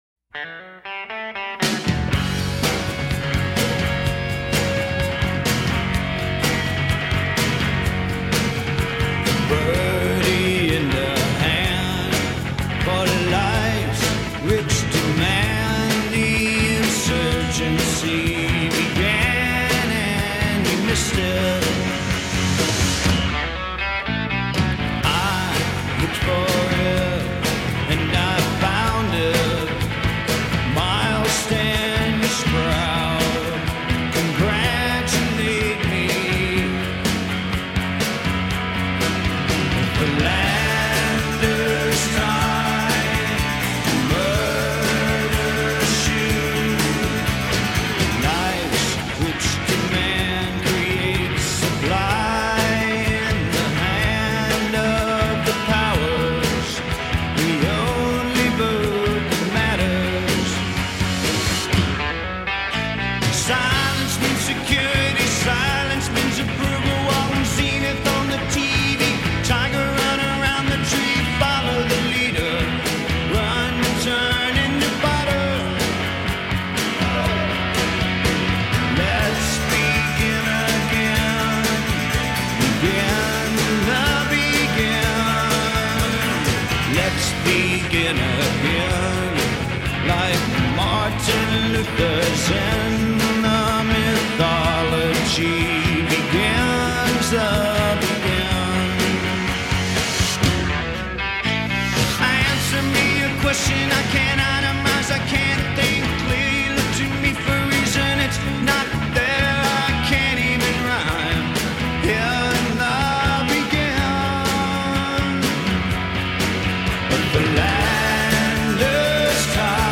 This song isn’t just wonderful; it’s electric.